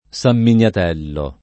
Sam minLat$llo] o Samminiatello [id.] top. (Tosc.) — sim. i cogn. Miniatelli, Sanminiatelli (e così lo scrittore Bino Sanminiatelli [b&no SamminLat$lli], 1896-1984; ma con -mm- il balì Samminiatelli [bal& SSamminLat$lli] satireggiato da G. Giusti)